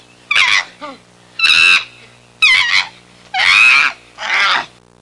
Chimps Playing Sound Effect
Download a high-quality chimps playing sound effect.
chimps-playing.mp3